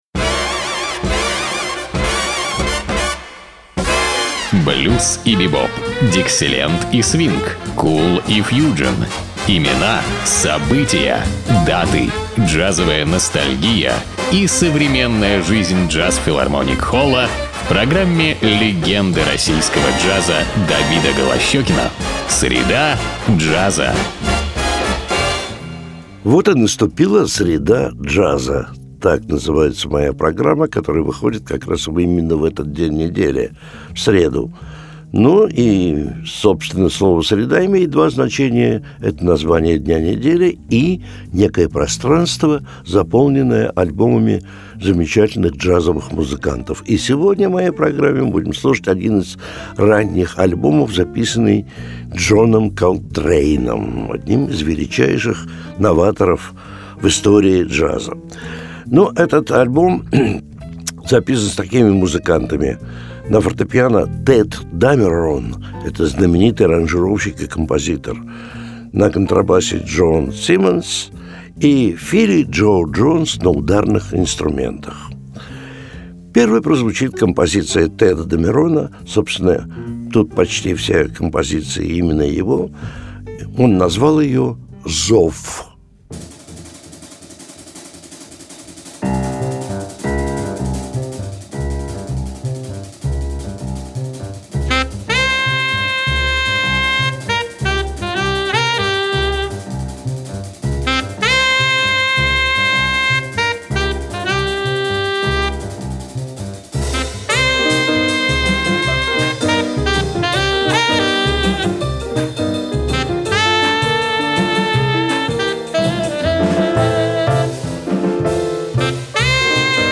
саксофонистом